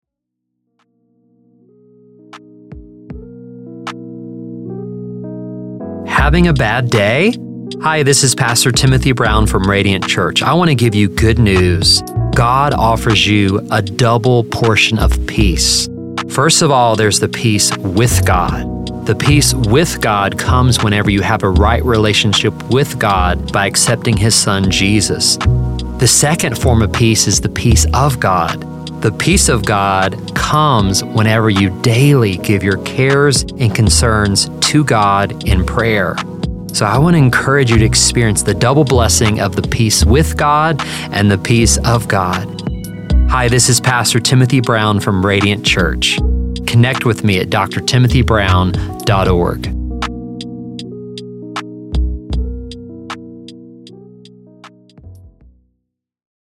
radio version